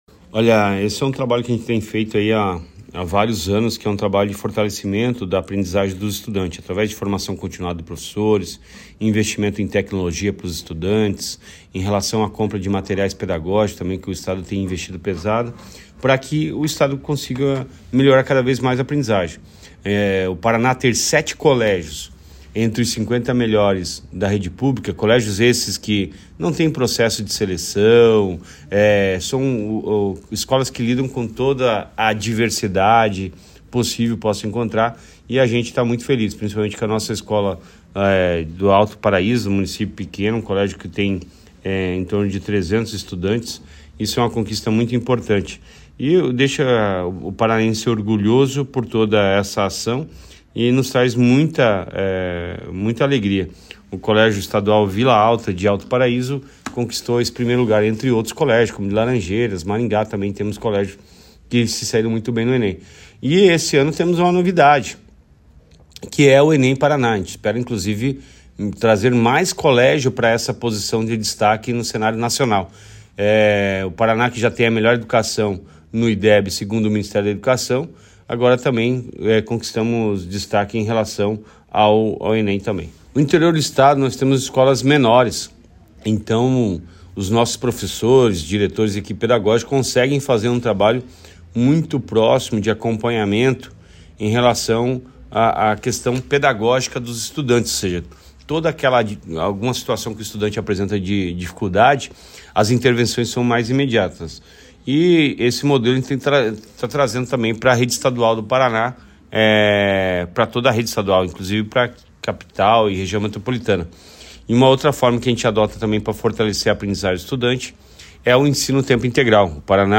Sonora do secretário da Educação, Roni Miranda, sobre o bom desempenho dos colégios da rede estadual no ENEM